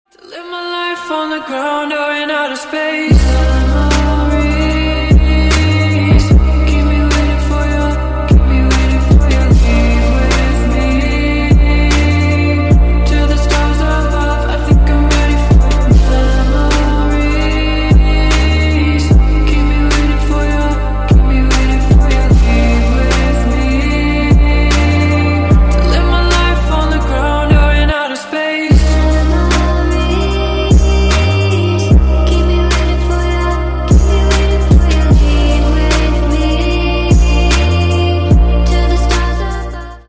Громкие Рингтоны С Басами » # Спокойные И Тихие Рингтоны
Танцевальные Рингтоны